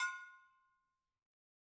BrakeDrum1_Hammer_v1_Sum.wav